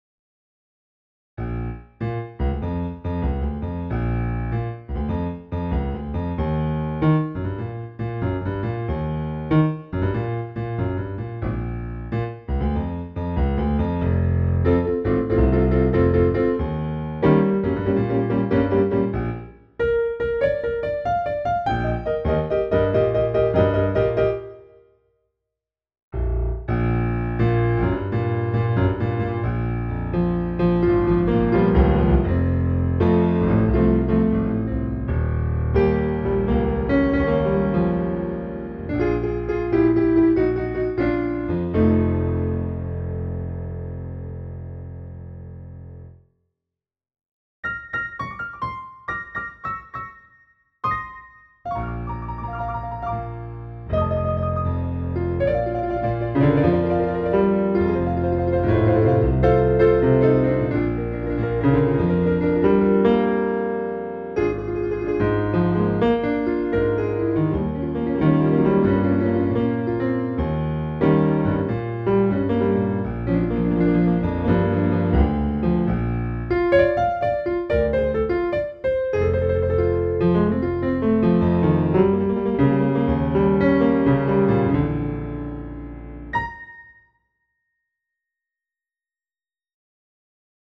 Lexicon MX400 digital in (creates reverb)